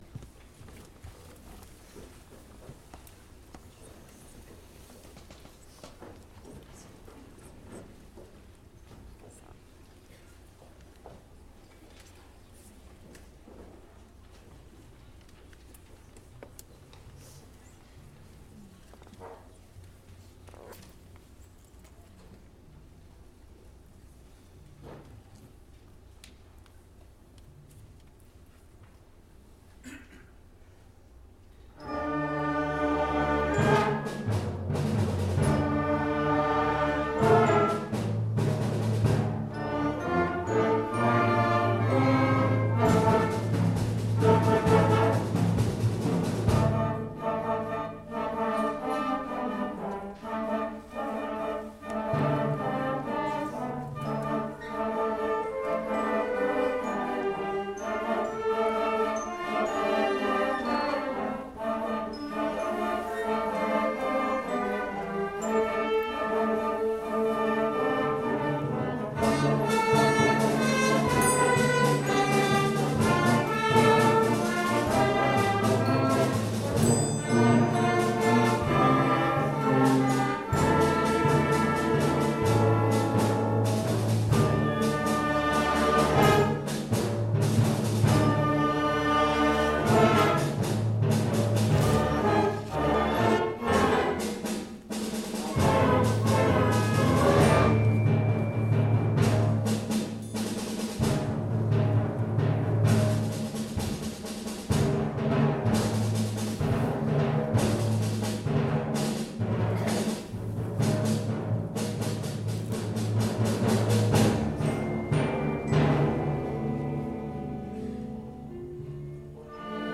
junior wind band